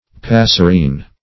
Passerine \Pas"ser*ine\, n. (Zool.)